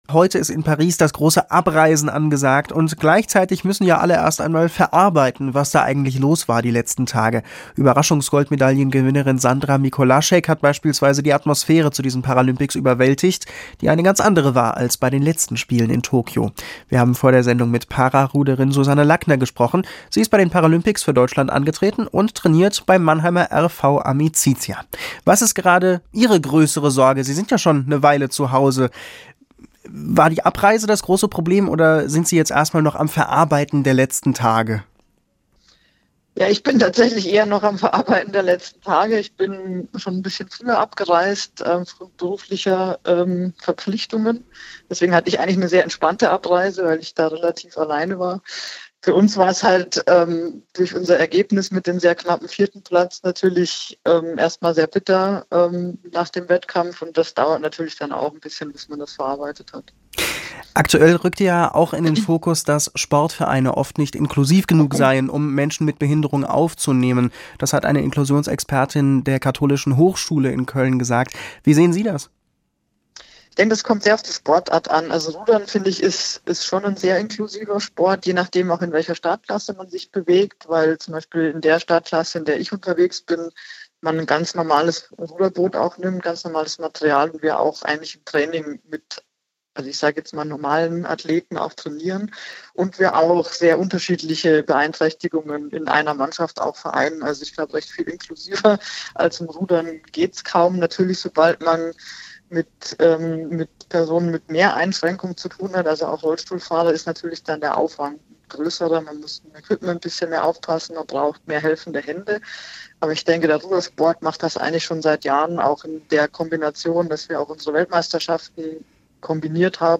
3. Radio